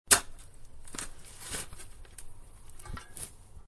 shovel5.ogg